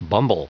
Prononciation du mot bumble en anglais (fichier audio)
Prononciation du mot : bumble